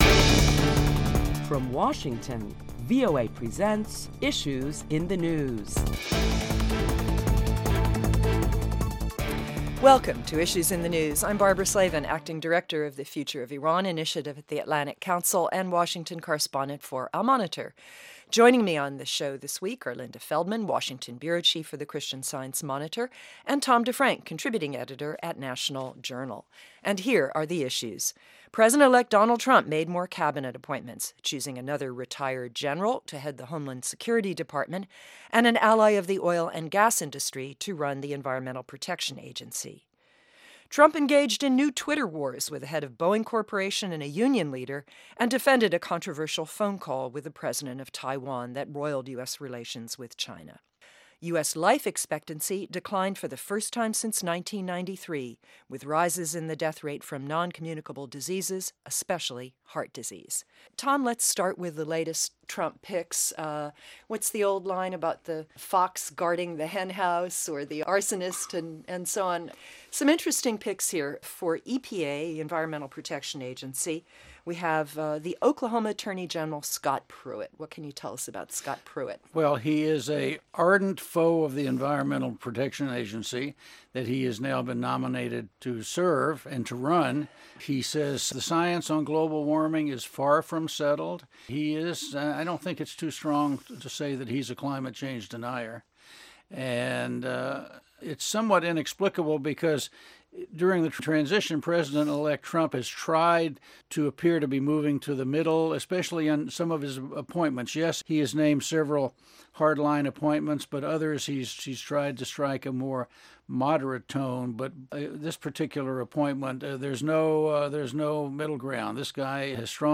Prominent Washington correspondents discuss topics making headlines around the world.